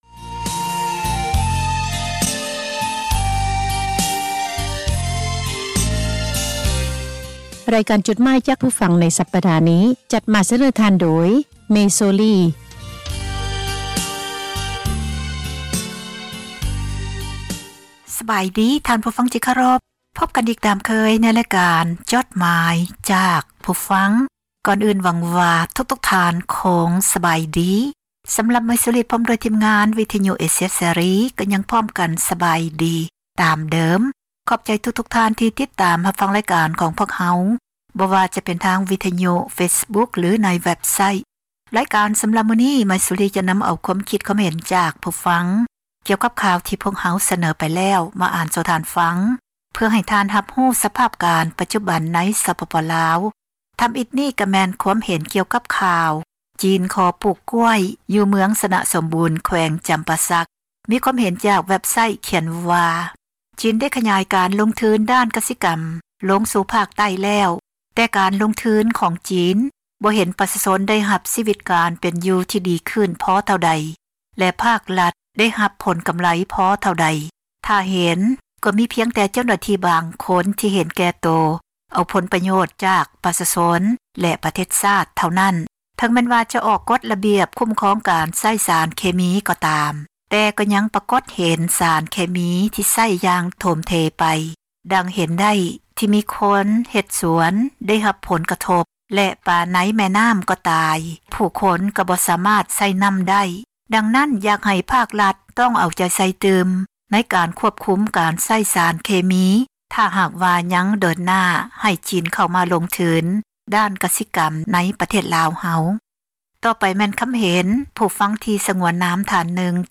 ອ່ານຈົດໝາຍ, ຄວາມຄຶດຄວາມເຫັນ ຂອງທ່ານ ສູ່ກັນຟັງ ເພື່ອເຜີຍແຜ່ ທັສນະ, ແນວຄິດ ທີ່ສ້າງສັນ, ແບ່ງປັນ ຄວາມຮູ້ ສູ່ກັນຟັງ.